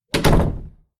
snd_doorclose.ogg